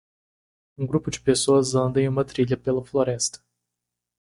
Pronounced as (IPA) /ˈtɾi.ʎɐ/